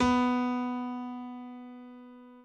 b_pianochord_v100l1-2o4b.ogg